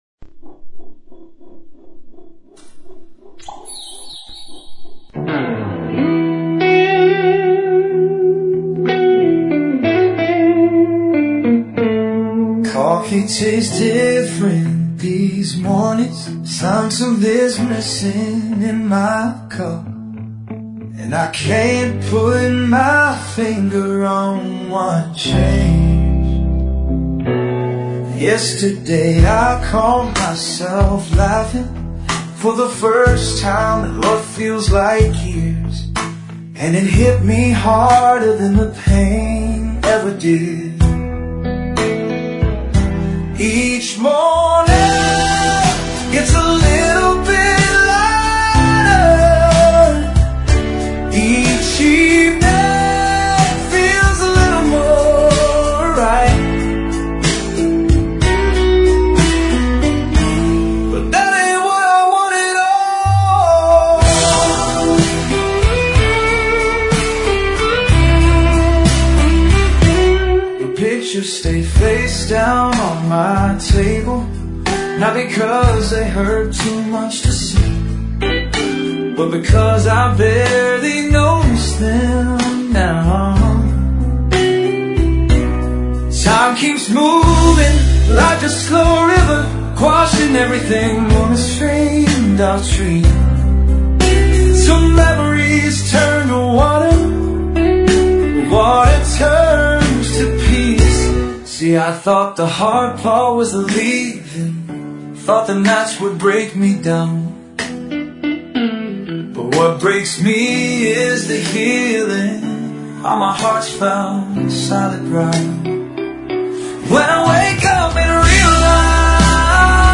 Best Soulful Blues Ballad